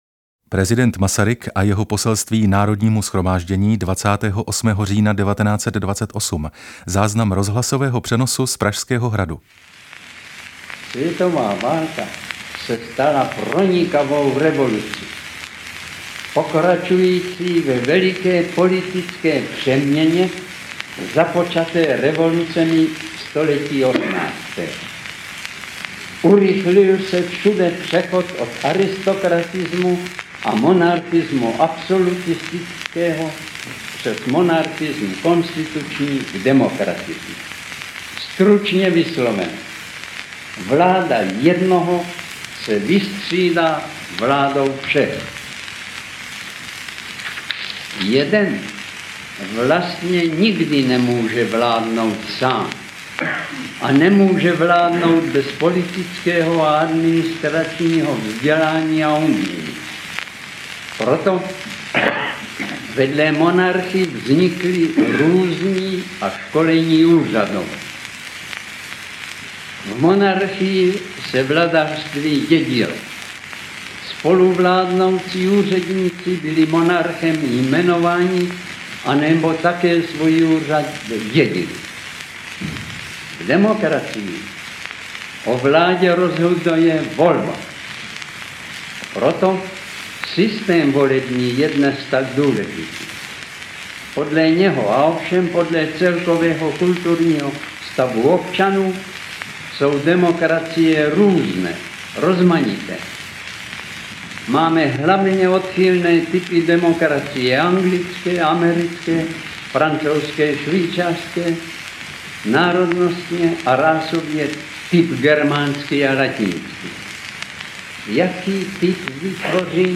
T. G. Masaryk na rozhlasových vlnách - audiokniha obsahující záznamy promluv Tomáše Garrigue Masaryka i hovorů osobností o něm.
Ukázka z knihy
Uslyšíte proslov k Národnímu shromáždění z roku 1928, proslovy k dětem nebo anglický pozdrav k výročí narození amerického prezidenta Washingtona.